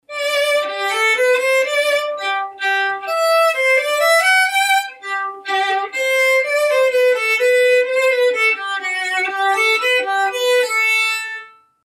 Игра на скрипке